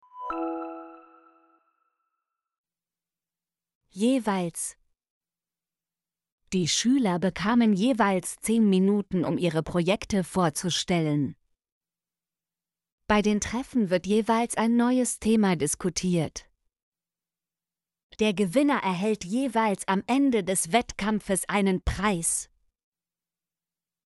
jeweils - Example Sentences & Pronunciation, German Frequency List